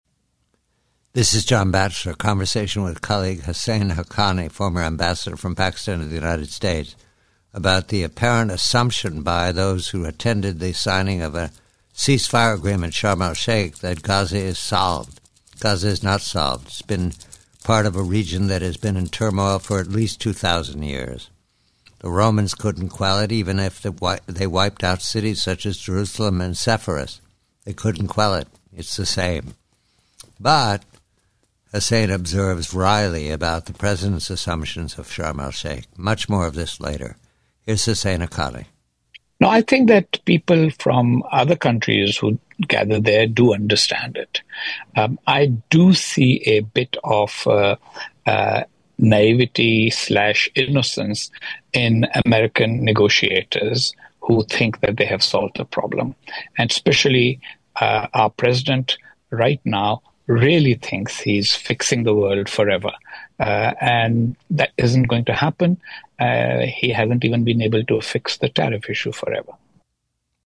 Husain Haqqani speaks with John Batchelor about the apparent assumption that Gaza is "solved" following a ceasefire signing in Sharm el-Sheikh. Haqqani observes naivety in American negotiators who believe they are fixing the world forever.